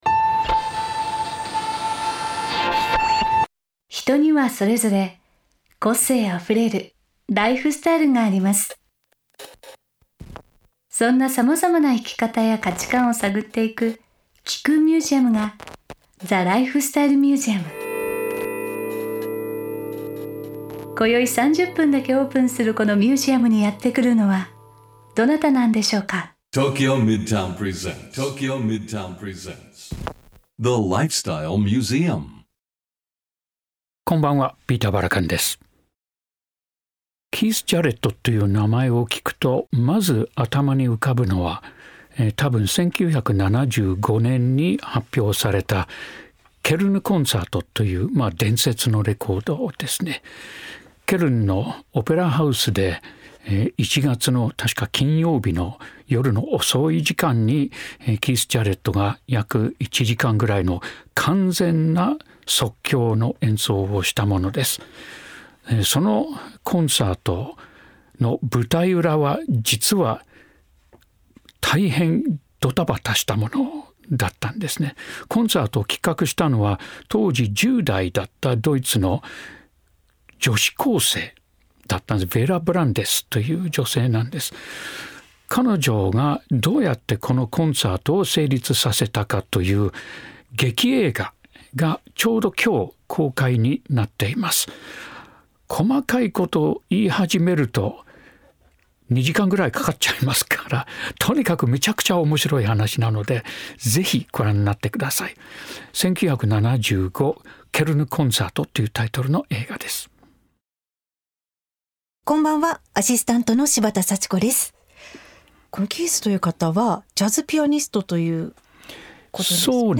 4月10日OAのゲストは、指揮者の佐渡裕さんです。